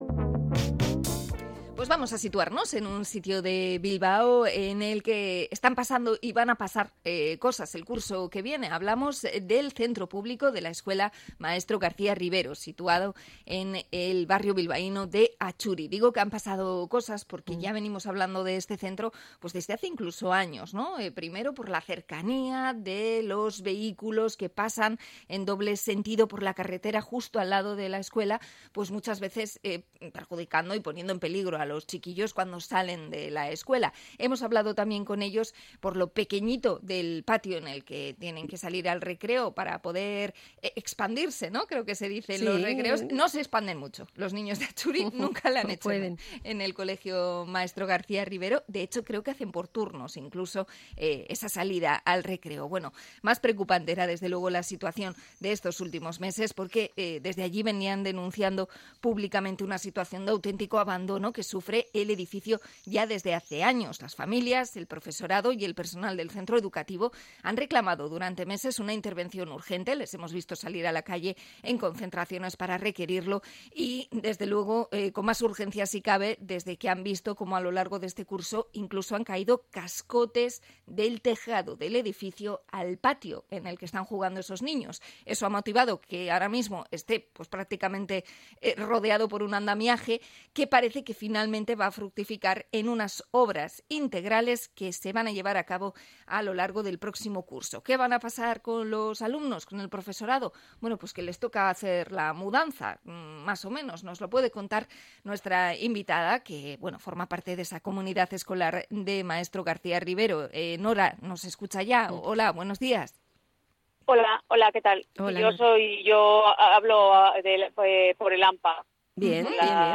Entrevista a amatxu de la escuela García Rivero de Atxuri